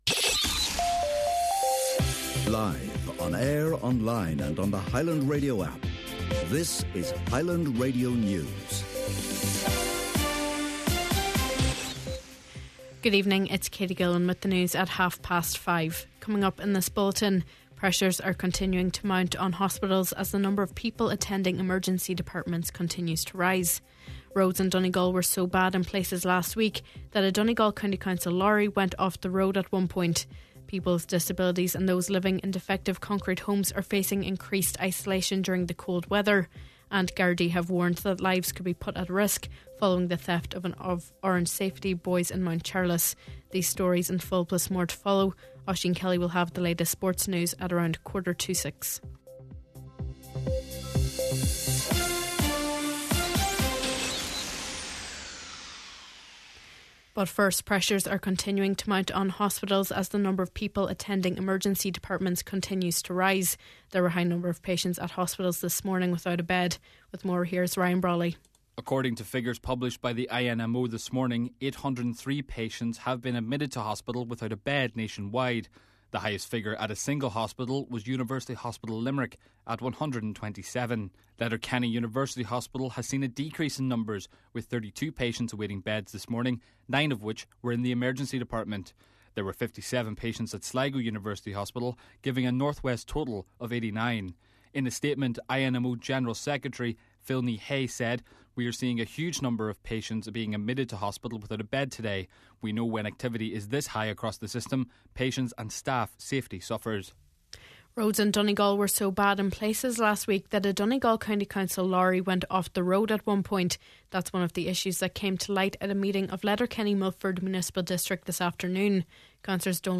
Main Evening News, Sport and Obituary Notices – Tuesday January 13th